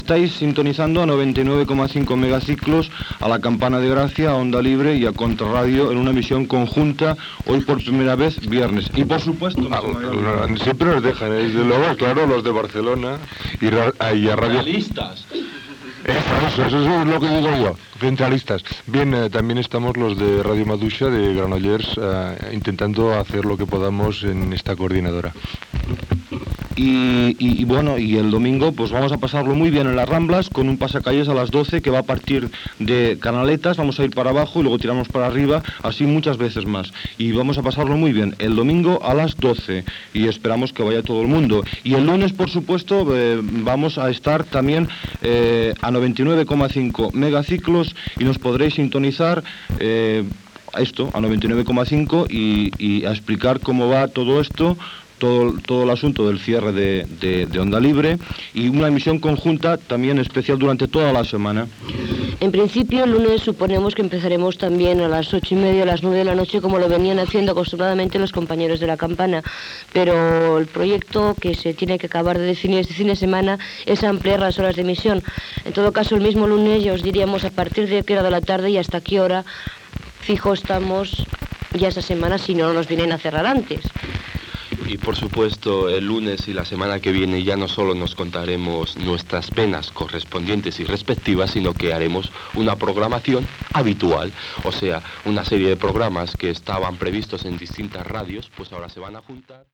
Emissió conjunta feta des de les instal·lacions de La Campana de Gràcia.